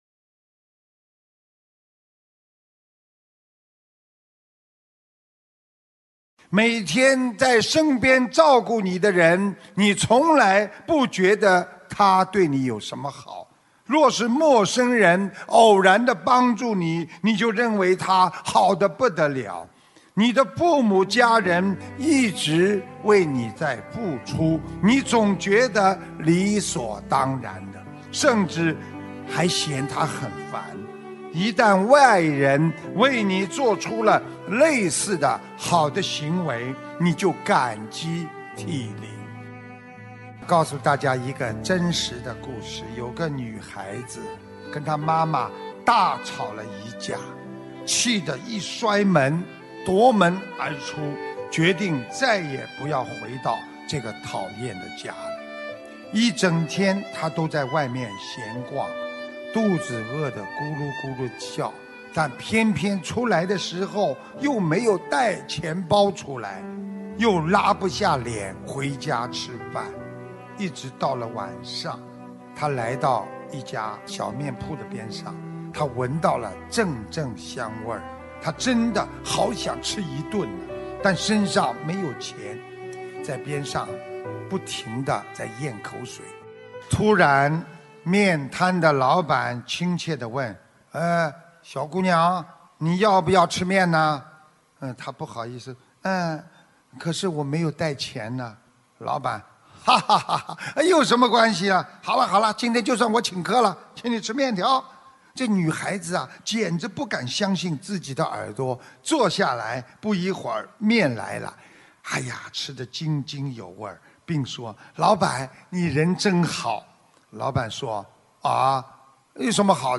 音频：师父讲故事《一碗面的故事》摘录_2018年10月14日 纽约 – 天涯佛子若比邻 法喜充满听佛音！